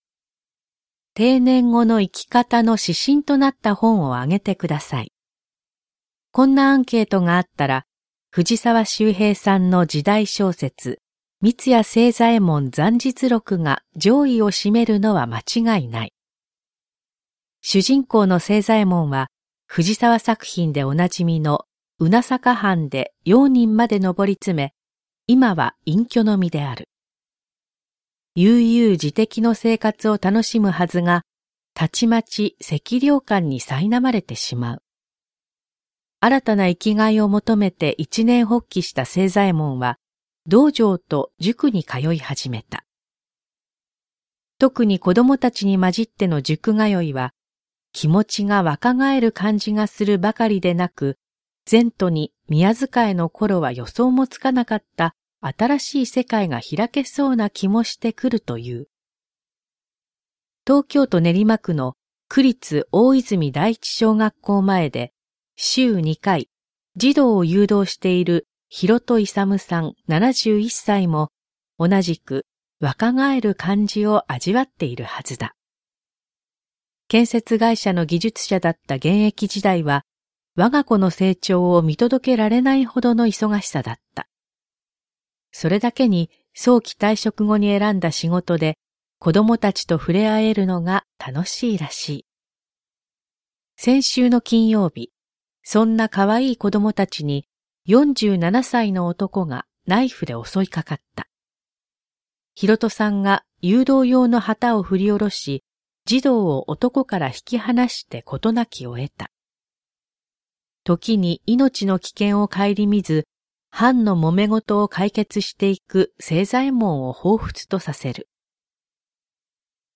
全国240名の登録がある局アナ経験者がお届けする番組「JKNTV」
産経新聞1面のコラム「産経抄」を、局アナnetメンバーが毎日音読してお届けします。